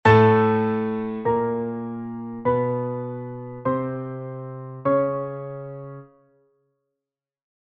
der-Ton-Heses.mp3